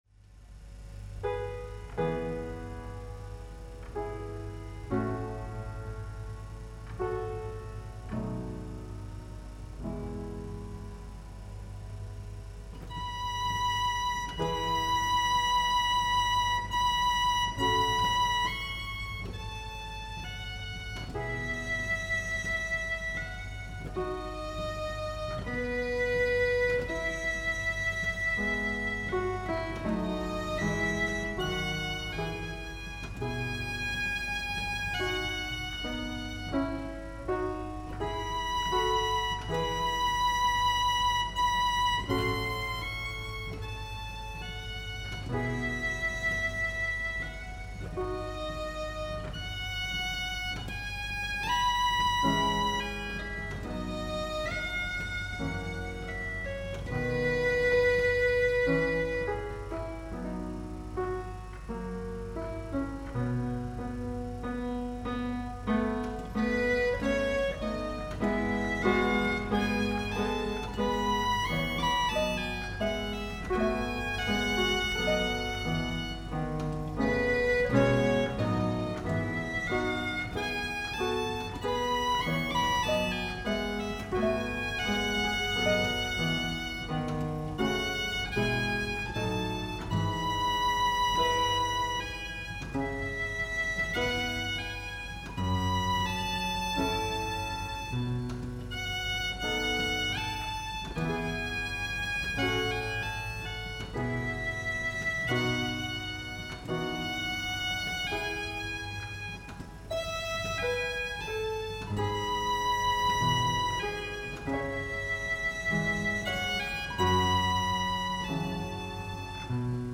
Violin player, Magic Flute, Mozart